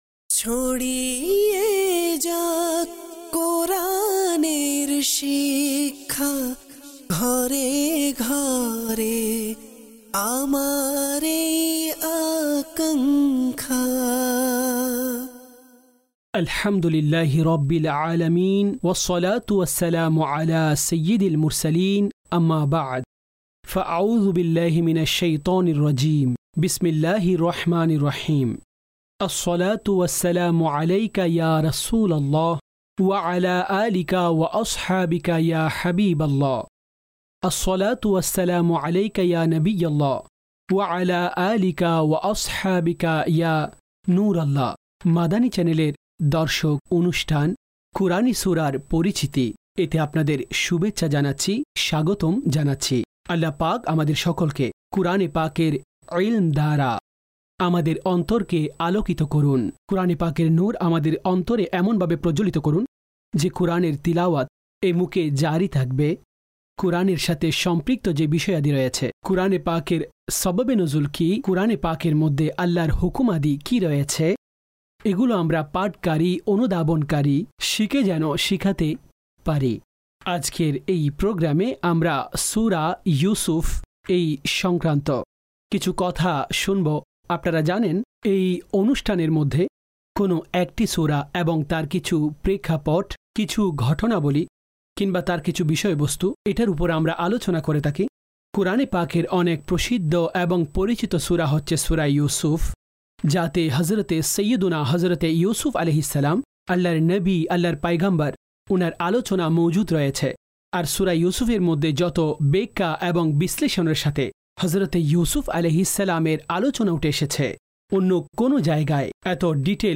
কুরআনি সূরার পরিচিতি (বাংলায় ডাবিংকৃত) EP# 23